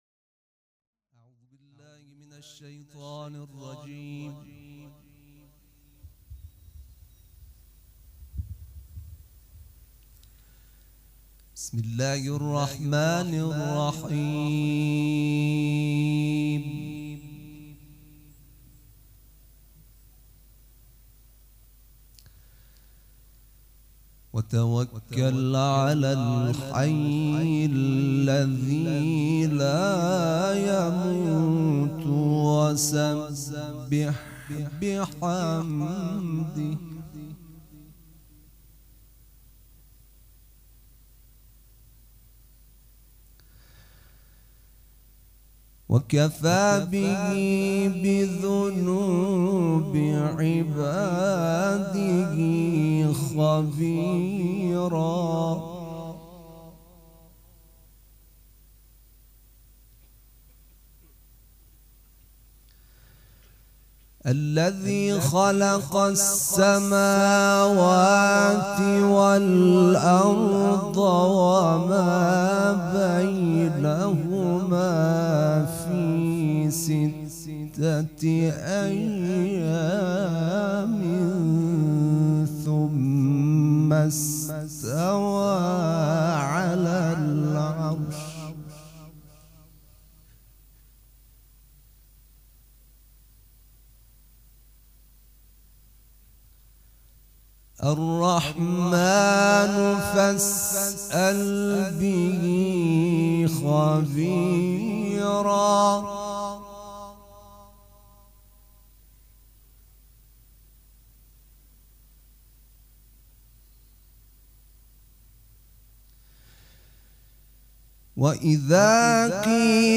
دوشنبه 29 بهمن 1397 هیئت ریحانه الحسین سلام الله علیها
قرائت قرآن